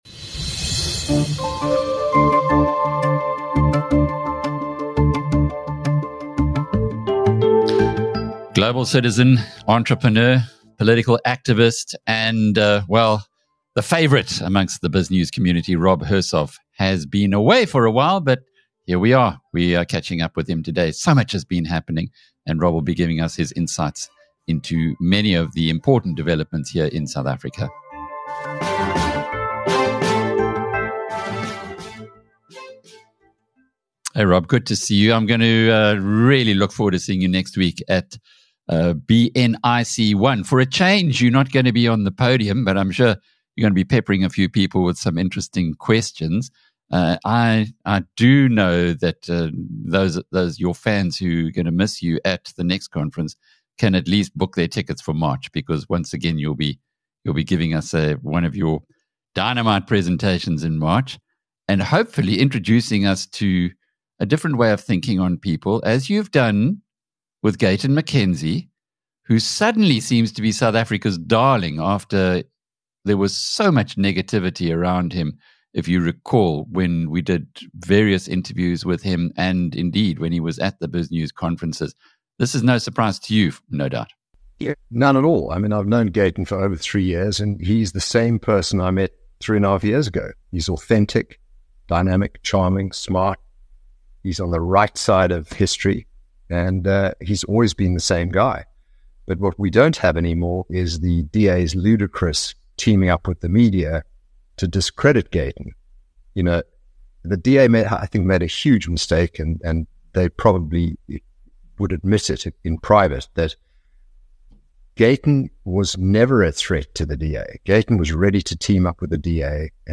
He does so here with trademark frankness in a thoughtful interview